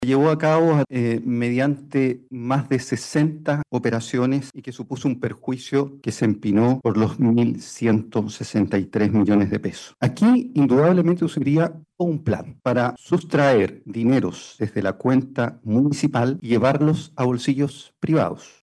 Durante la instancia el fiscal jefe de la Unidad Regional Anticorrupción de Valparaíso, Claudio Reveco, remarcó que los involucrados concertaron y planificaron este desfalco que se llevó a cabo mediante más de 60 operaciones.